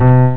INTERACTIVE PIANO
the note should sound out.